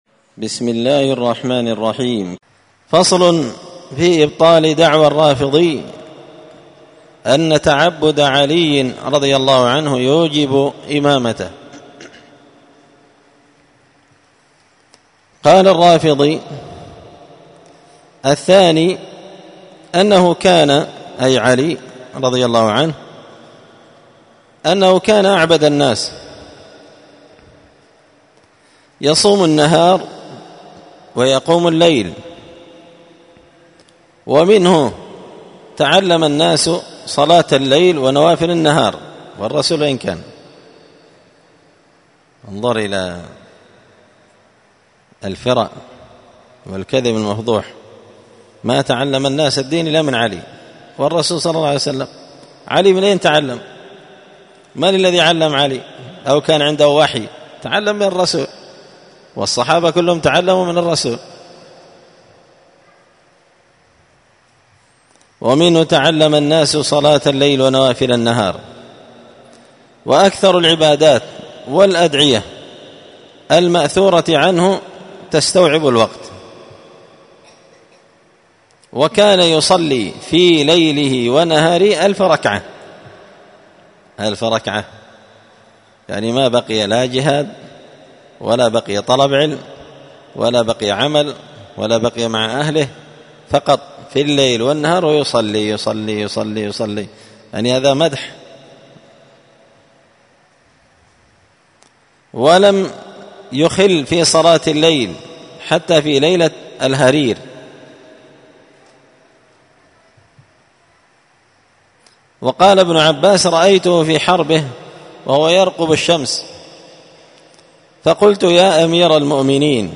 الدرس السادس بعد المائتين (206) فصل في إبطال دعوى الرافضي أن تعبد علي يوجب إمامته